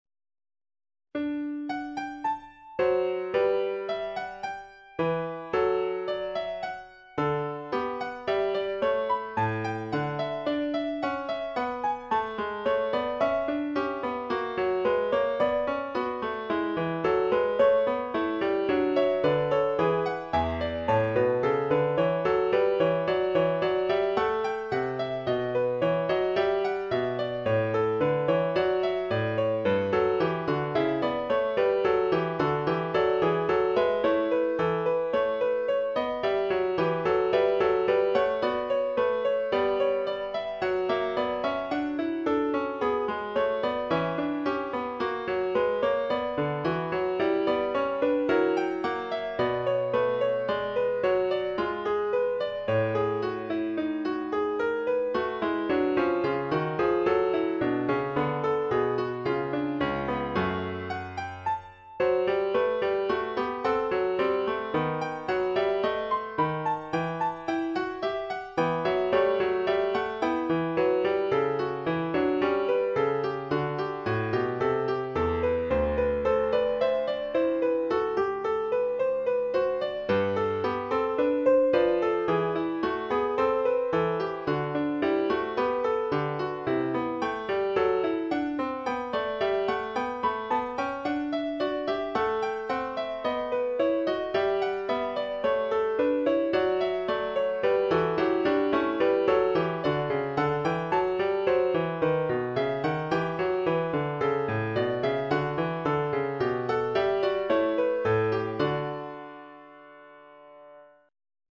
Esempi di musica realizzata con il sistema temperato